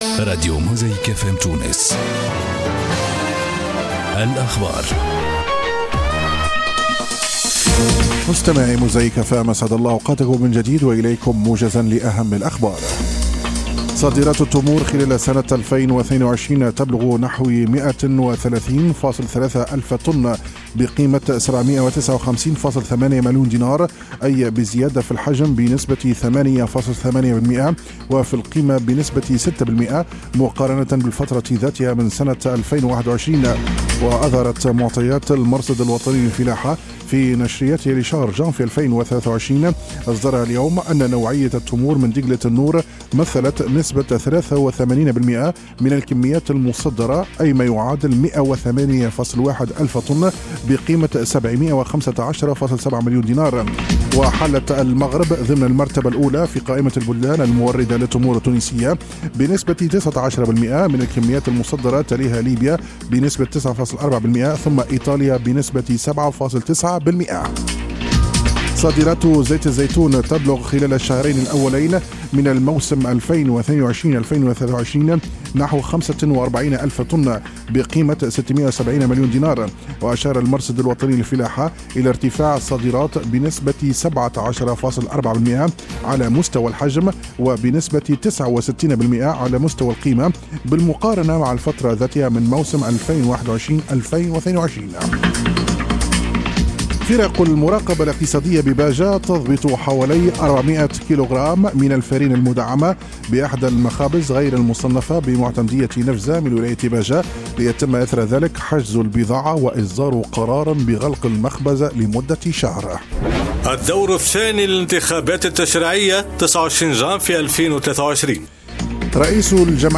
نشرات أخبار جانفي 2023